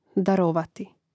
daròvati darovati